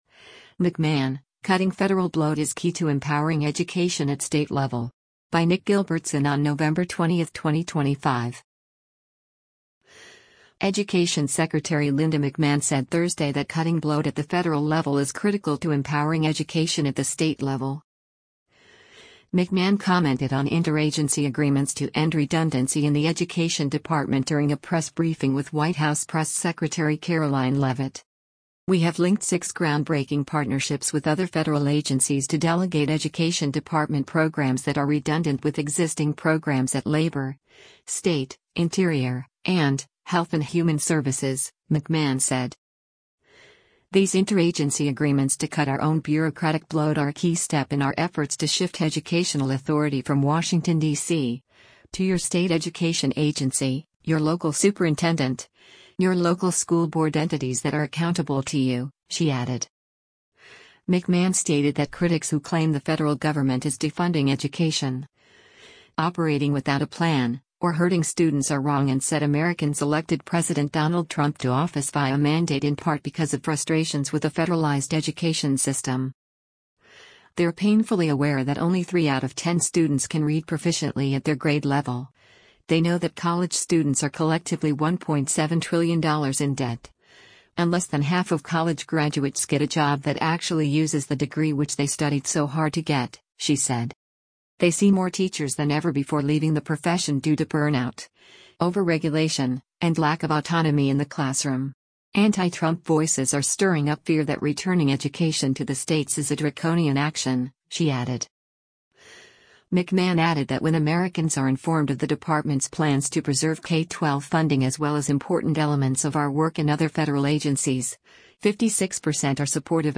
McMahon commented on interagency agreements to end redundancy in the Education Department during a press briefing with White House press secretary Karoline Leavitt.